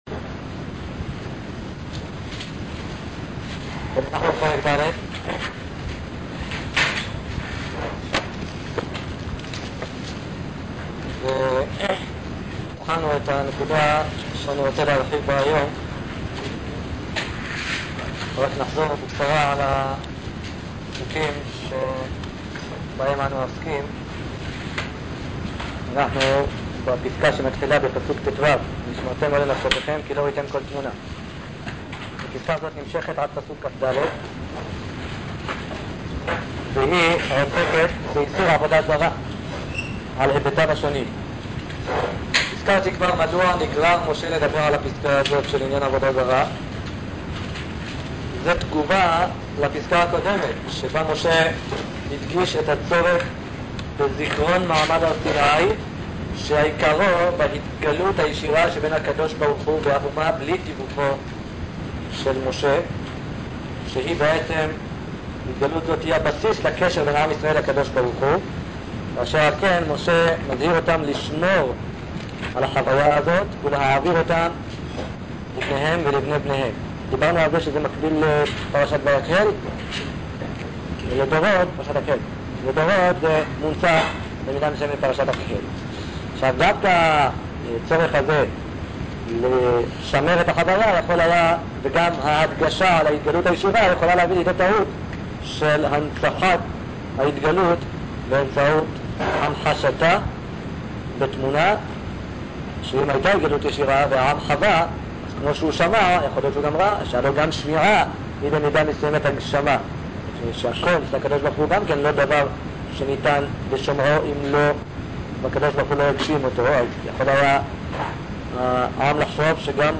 "Quality" column refers, of course, to recording quality.